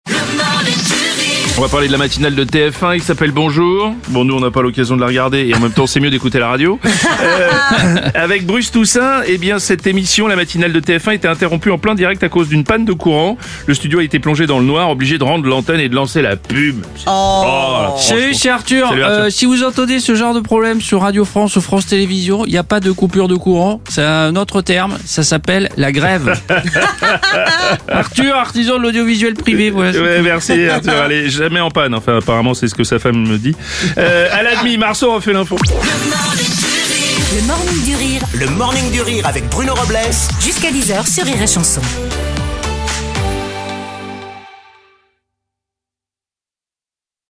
débriefe l’actu en direct à 7h30, 8h30, et 9h30.